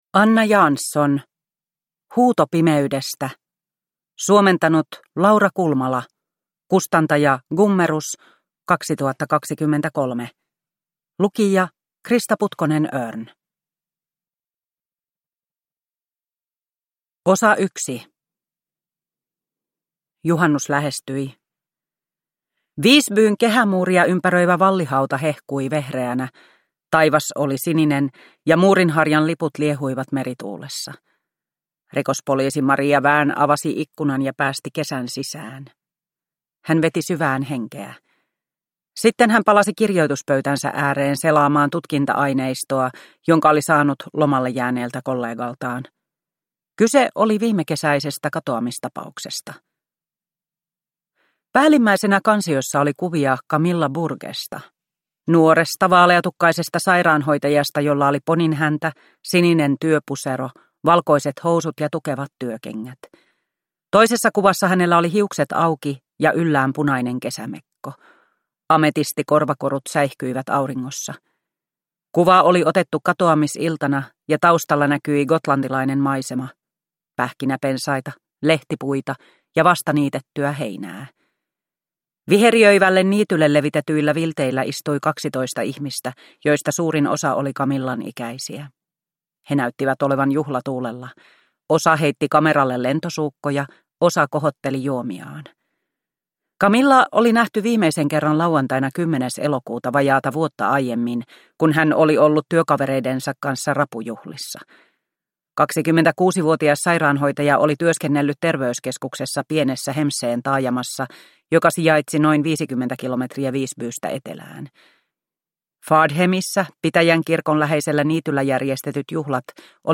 Huuto pimeydestä – Ljudbok – Laddas ner